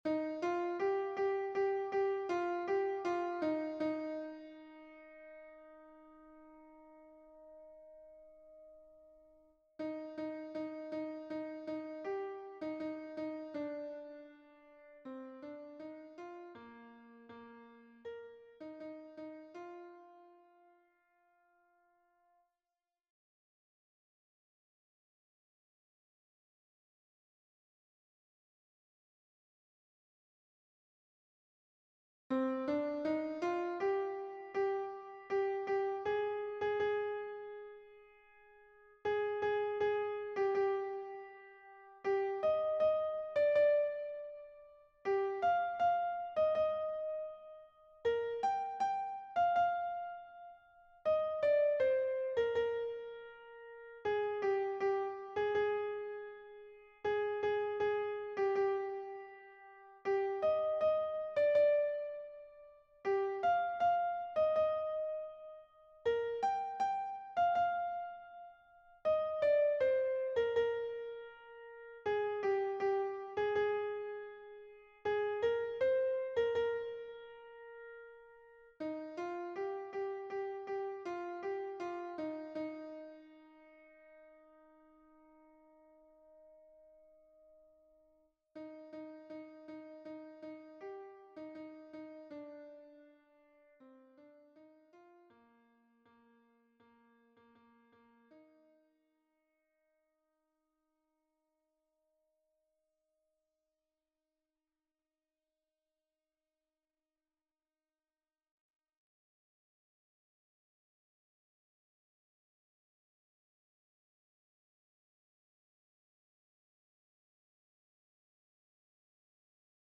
- Oeuvre pour choeur à 8 voix mixtes (SSAATTBB) a capella
MP3 version piano
Soprano